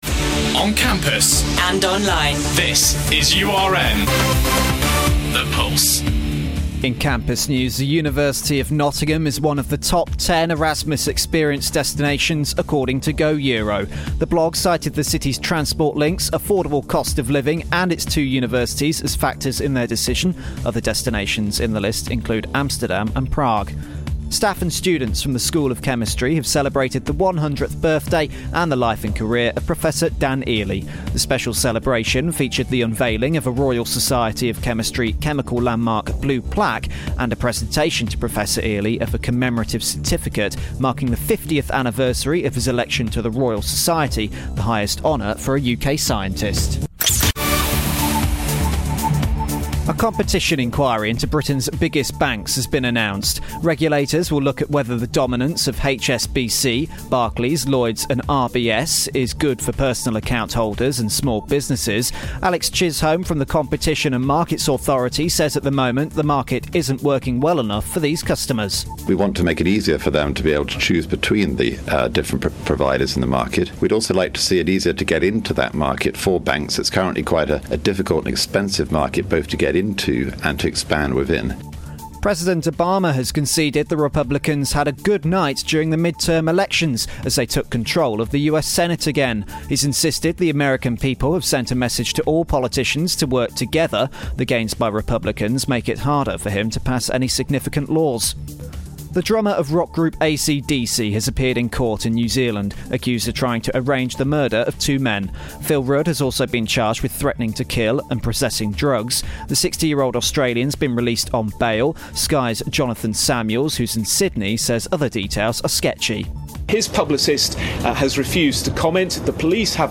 Your Latest Headlines - Thursday 6th November